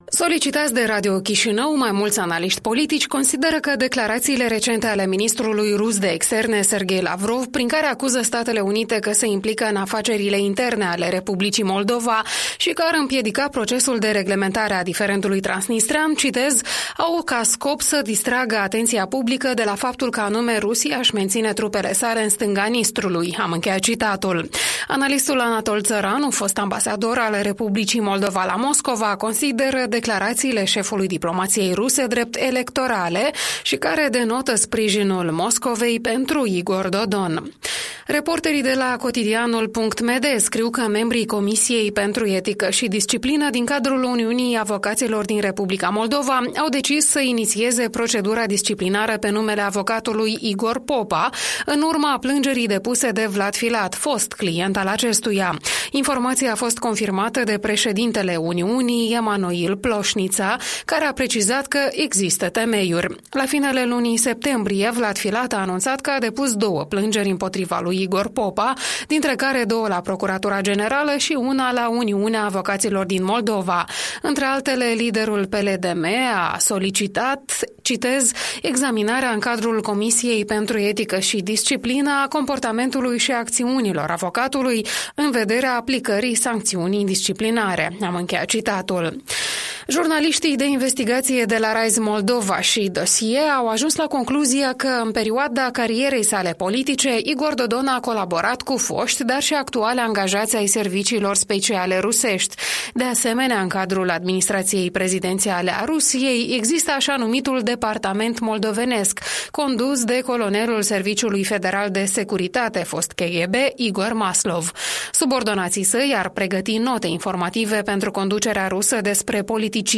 Revista matinală a presei la radio Europa Liberă.